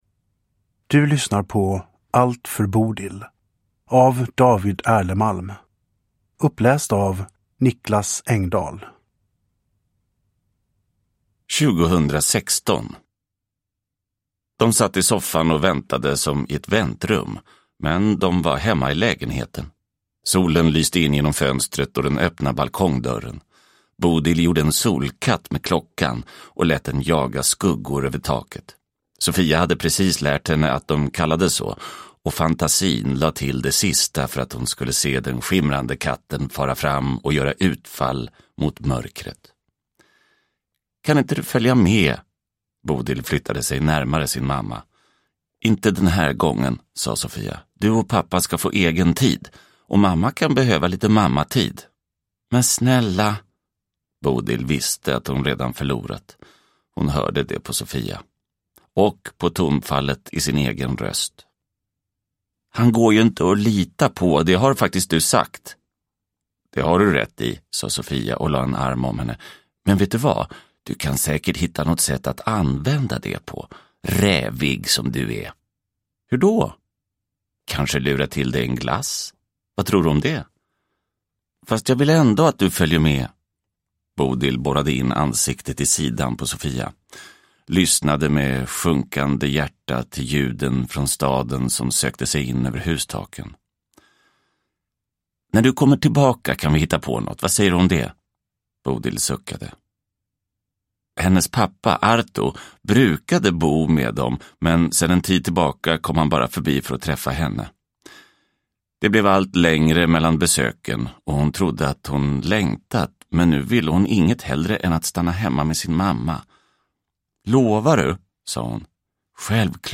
Allt för Bodil – Ljudbok – Laddas ner